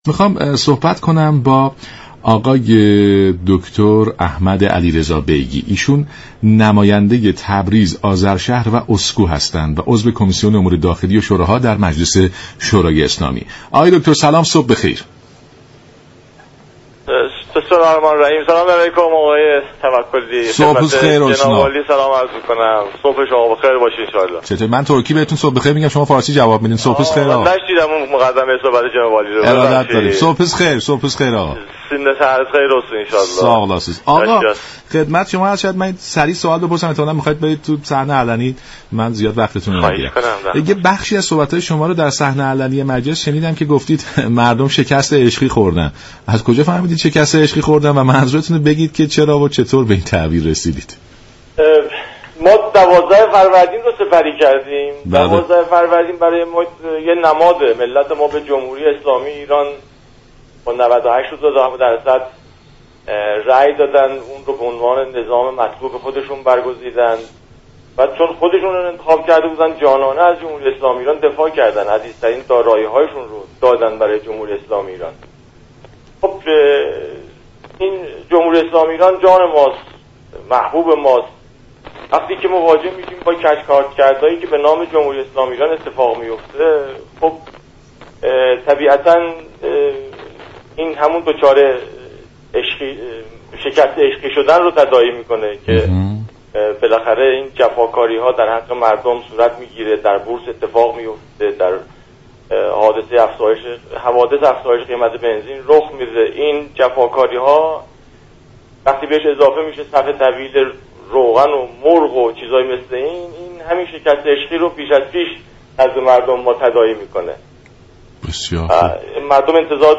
نماینده تبریز، آذرشهر و اسكو و عضو كمیسیون امور داخلی و شوراهای مجلس گفت:تفاهم نامه ایران و چین نقشه راهی است كه طرفین قرارداد ظرفیت های خود را به اشتراك می گذارند.